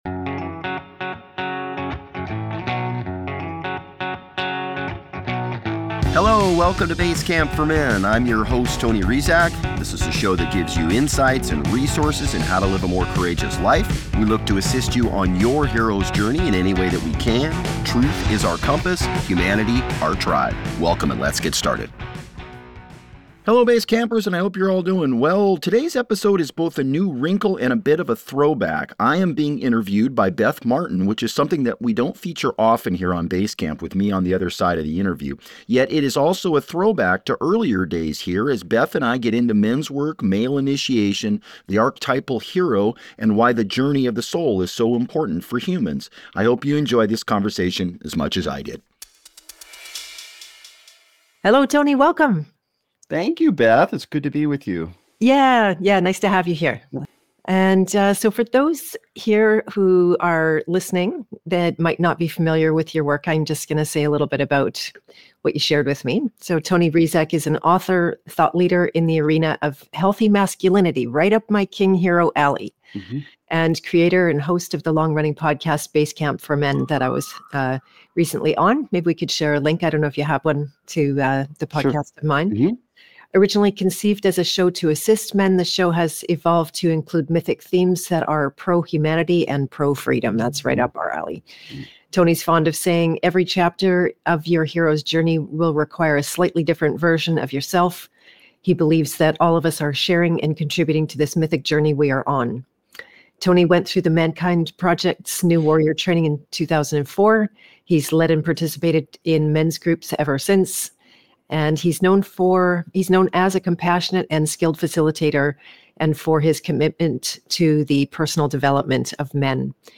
E273 Final Thoughts Well, I hope you enjoyed that conversation.